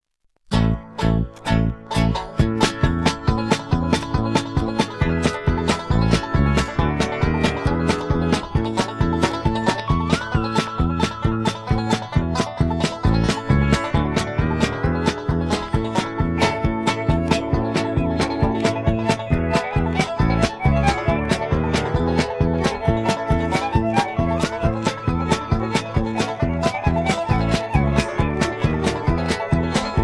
(two instrumentals)